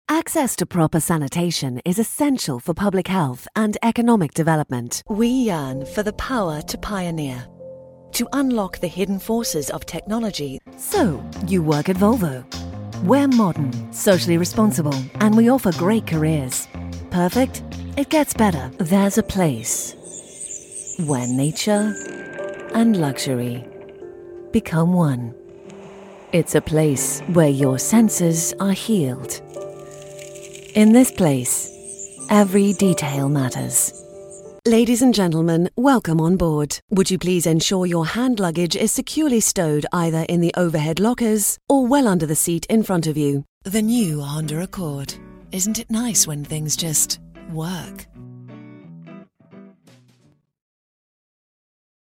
Female
Adult (30-50)
In a British accent I invoke an intelligent, sophisticated and meaningful sound that has seen me cast in corporate, documentary and upscale commercial work.
British Accent Demo
All our voice actors have professional broadcast quality recording studios.